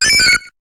Cri d'Axoloto dans Pokémon HOME.